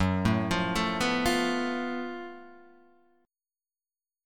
GbmM7bb5 chord